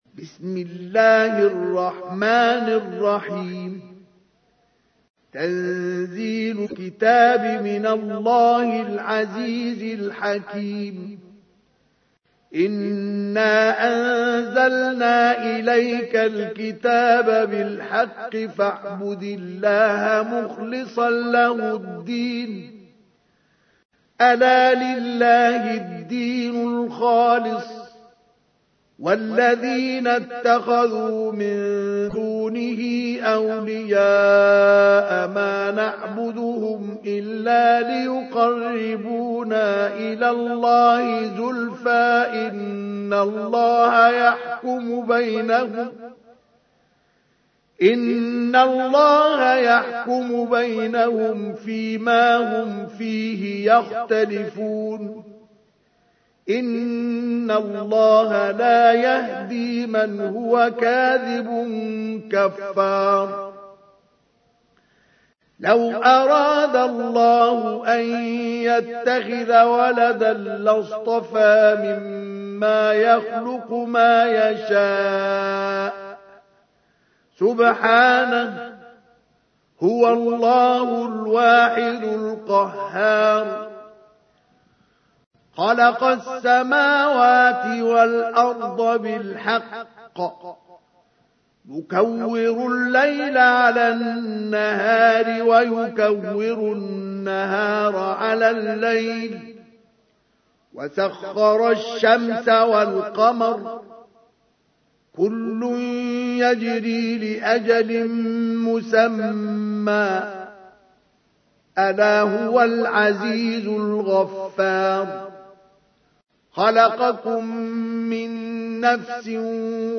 تحميل : 39. سورة الزمر / القارئ مصطفى اسماعيل / القرآن الكريم / موقع يا حسين